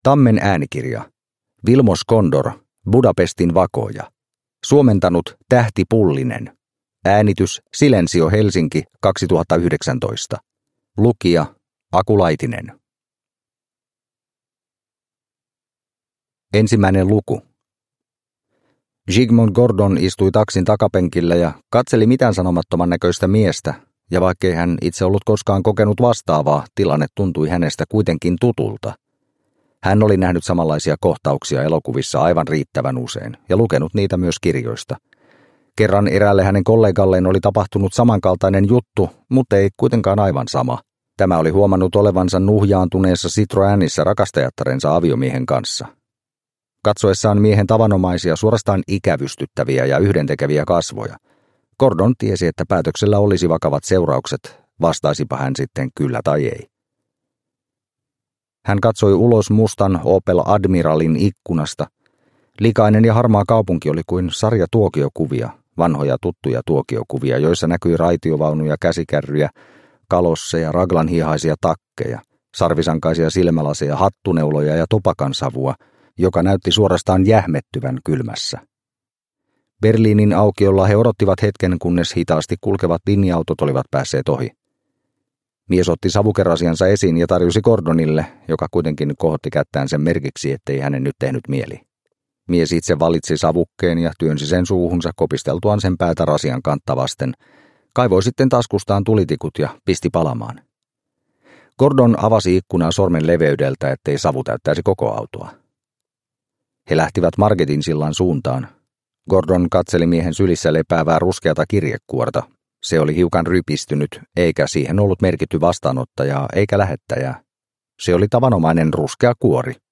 Budapestin vakooja – Ljudbok – Laddas ner